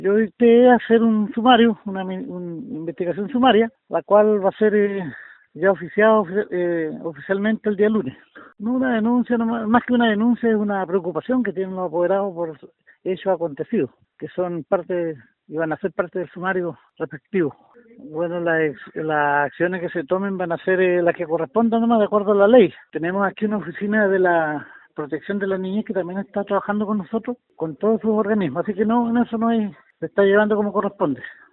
Aunque al ser consultado por La Radio, el jefe comunal, Jesús Morales, indicó que más que una denuncia es una preocupación.
alcalde-jesus-morales.mp3